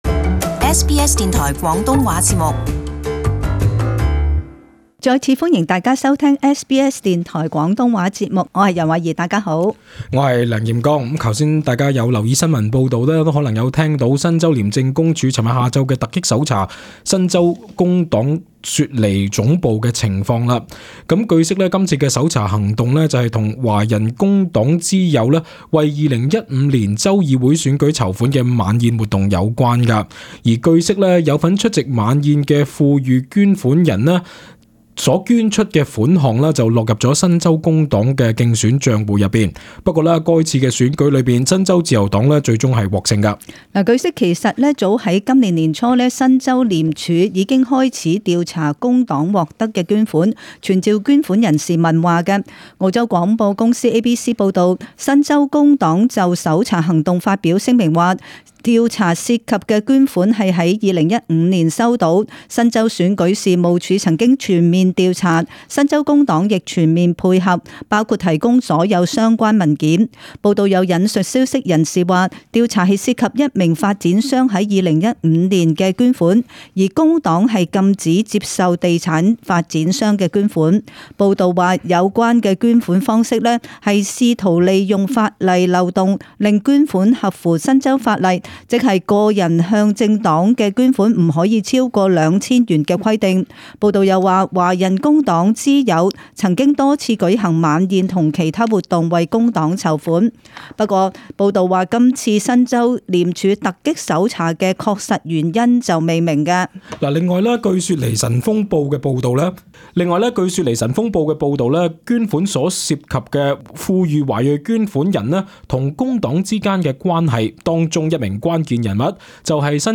【时事报导】廉署搜新州工党总部查捐款